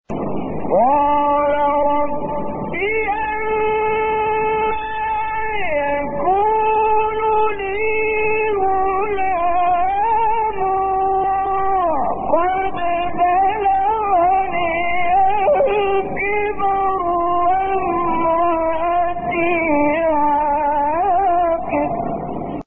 گروه شبکه اجتماعی: فرازهای صوتی از سوره آل‌عمران با صوت کامل یوسف البهتیمی که در مقام‌های مختلف اجرا شده است، می‌شنوید.
مقام نهاوند